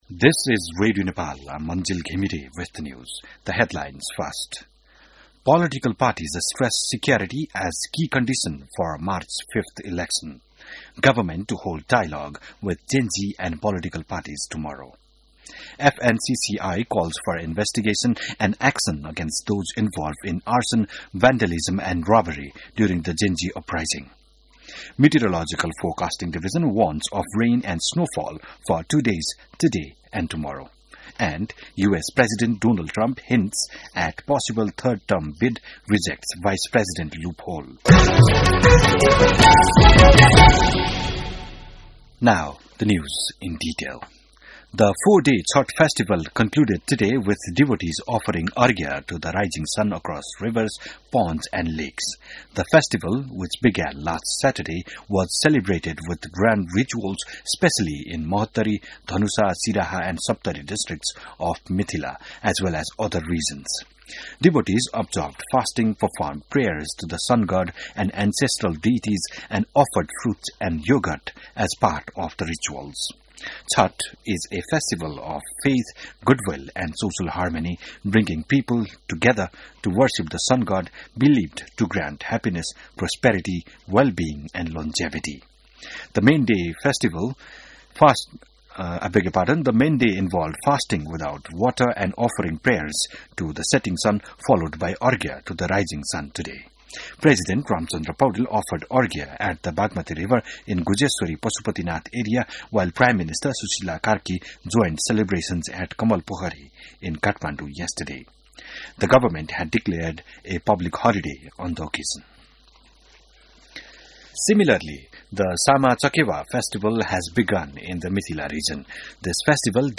बिहान ८ बजेको अङ्ग्रेजी समाचार : ११ कार्तिक , २०८२